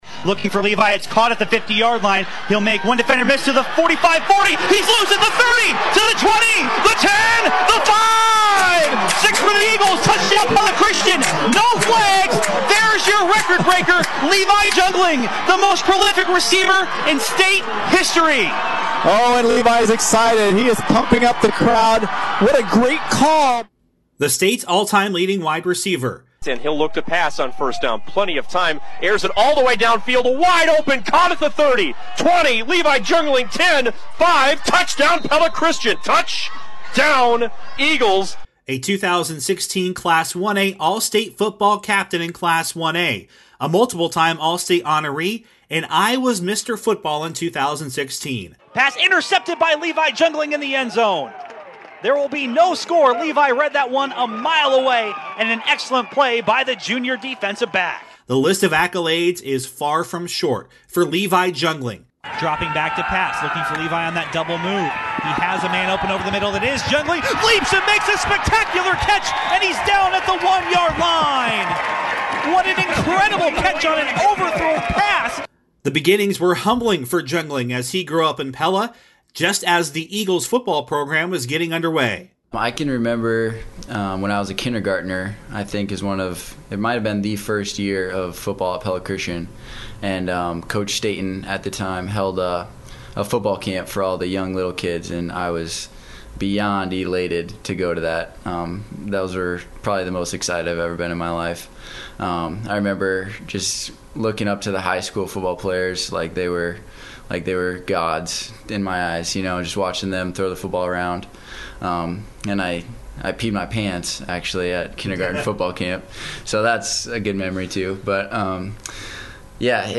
FULL INTERVIEW: